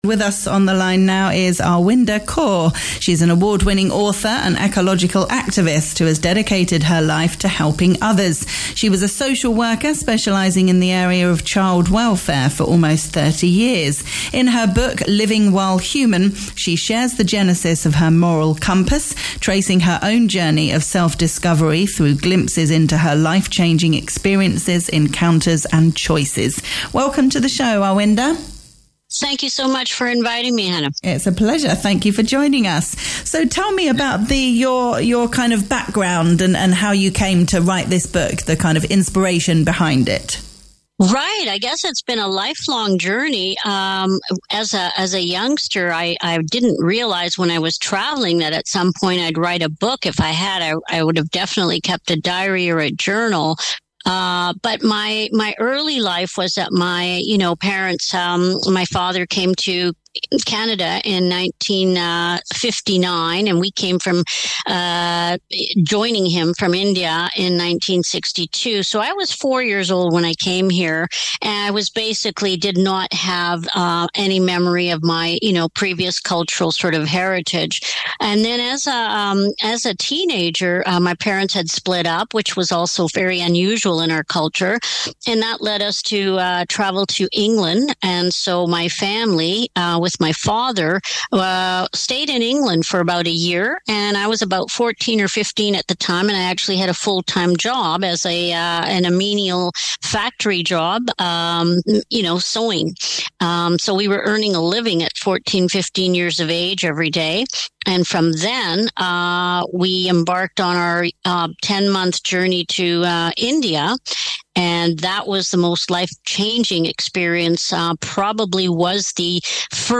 Talk Radio Europe Interview